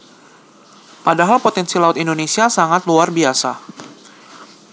Nexdata/Indonesian_Speech_Data_by_Mobile_Phone_Reading at refs/pr/1